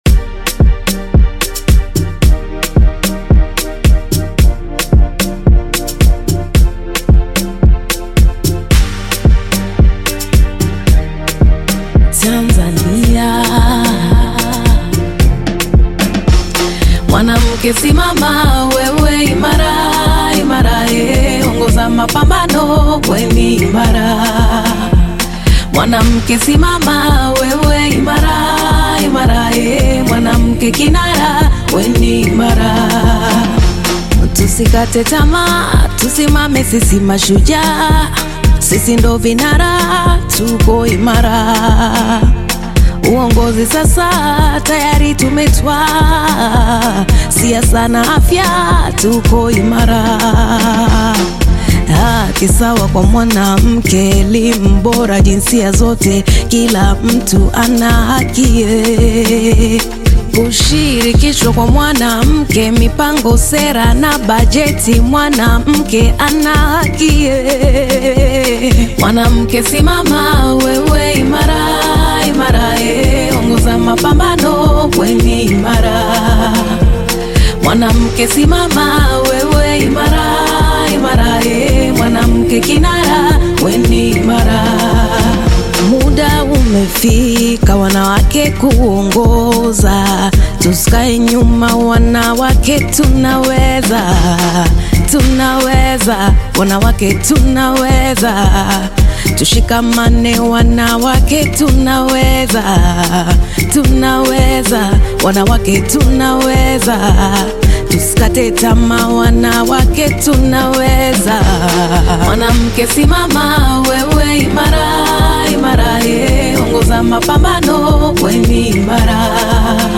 Tanzanian bongo flava artist, singer, and songwriter